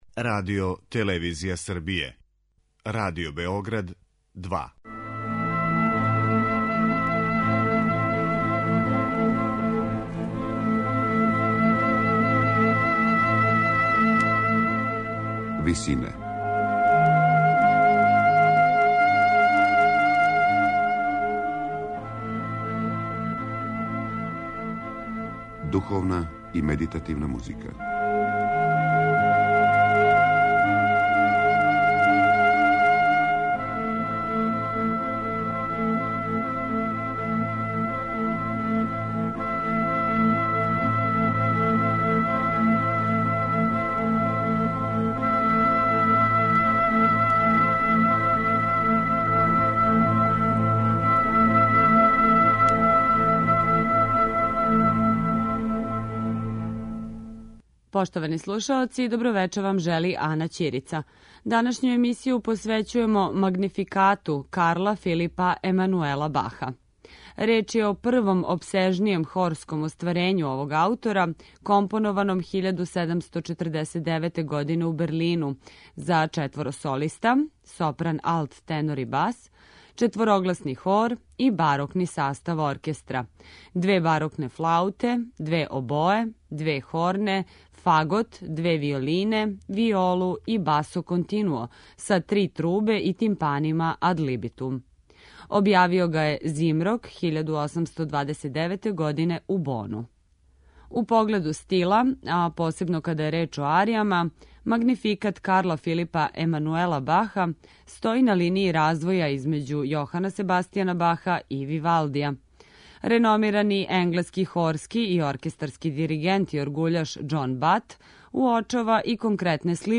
У данашњој емисији која је посвећена духовној и медитативној музици, слушаћете Магнификат Карла Филипа Емануела Баха.
На крају програма, у ВИСИНАМА представљамо медитативне и духовне композиције аутора свих конфесија и епоха.